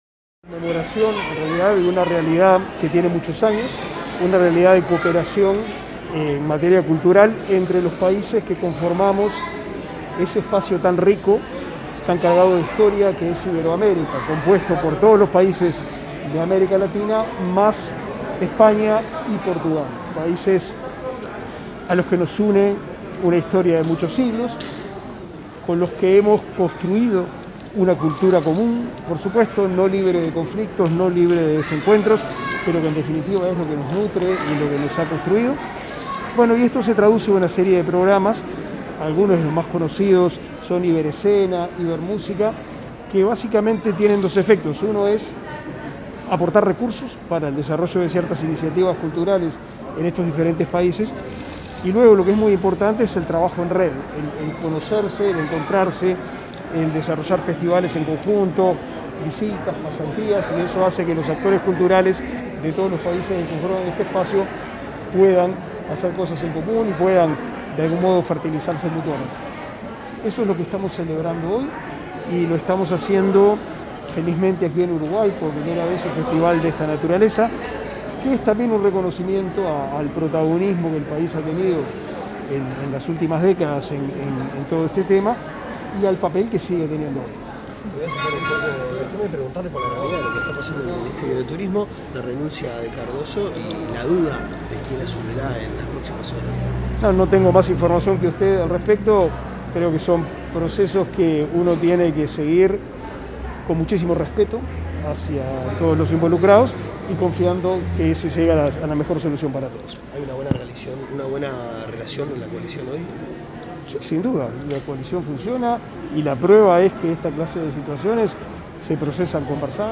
Declaraciones a la prensa del ministro de Educación y Cultura, Pablo da Silveira
Declaraciones a la prensa del ministro de Educación y Cultura, Pablo da Silveira 22/08/2021 Compartir Facebook X Copiar enlace WhatsApp LinkedIn Al cumplirse 30 años de las Cumbres Iberoamericanas de Jefes de Estado y de Gobierno, se realizó el festival “Iberoamérica viva”. Al finalizar la actividad, el ministro Da Silveira brindó declaraciones a la prensa.